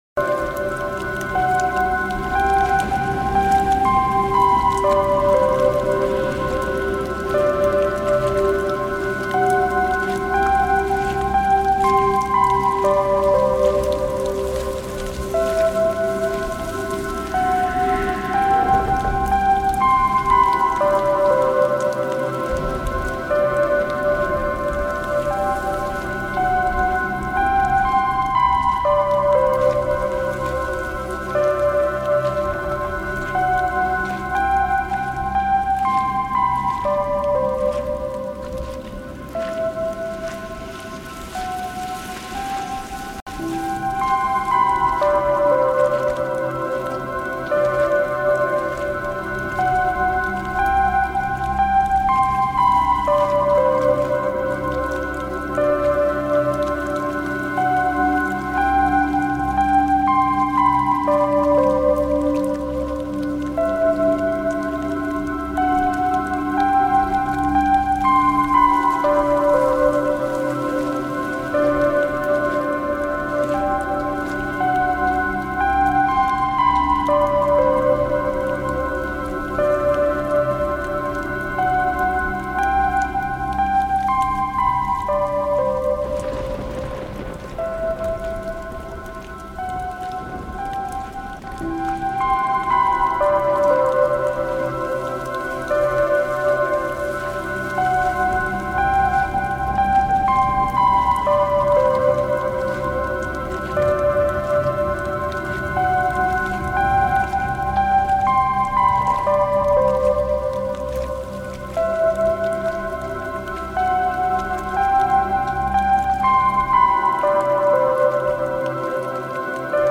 Rainy Window
3:44 · Nature